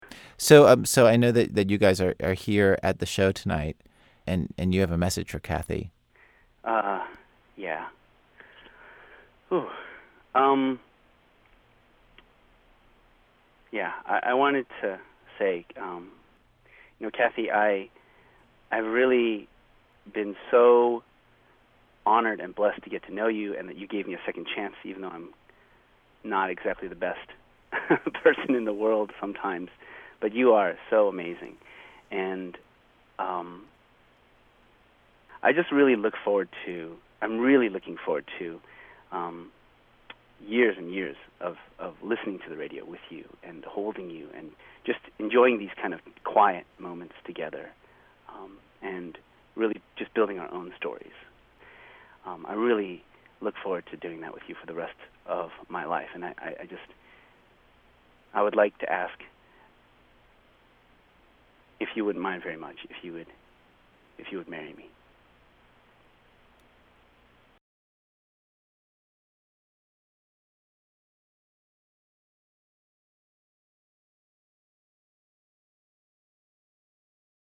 That seemed like something he should probably ask, not me, so we arranged for me to interview him over the phone two days before the speech.